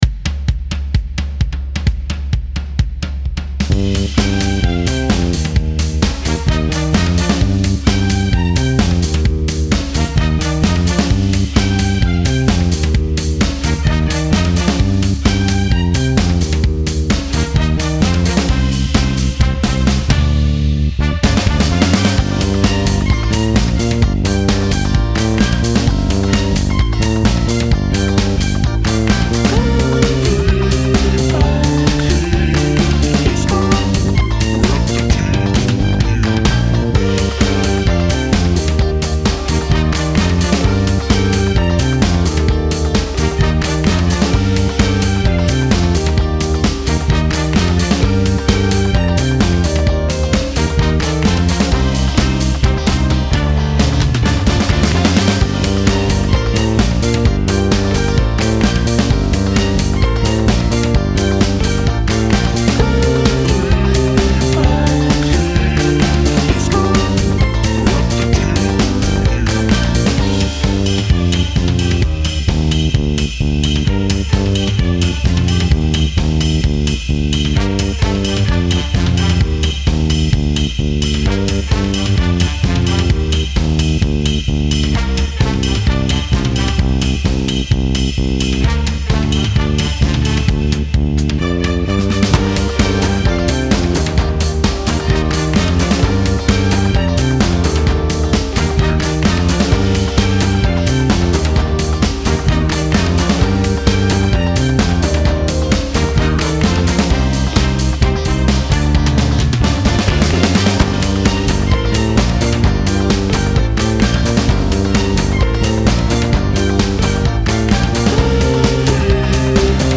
Style Style Oldies, Pop
Mood Mood Bright, Cool, Driving
Featured Featured Bass, Brass, Claps/Snaps +6 more
BPM BPM 130